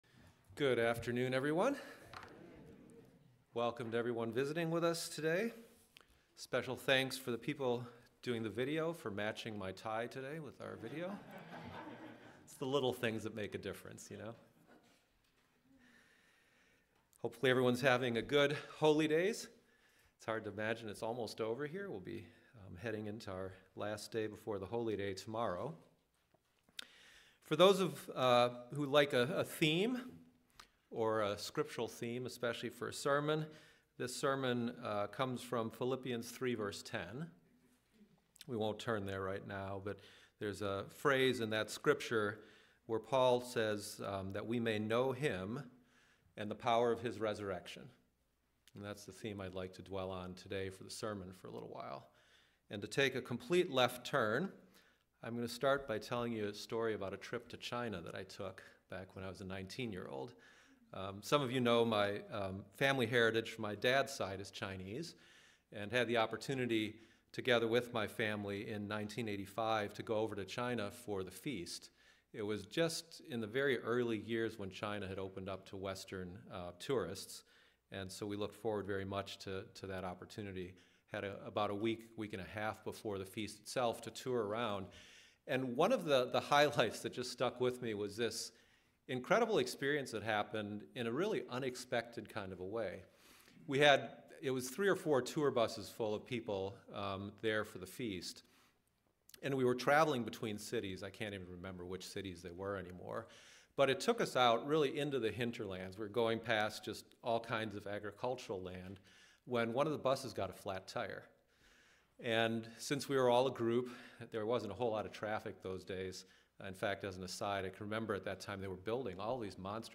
sermon_the_power_of_his_resurrection.mp3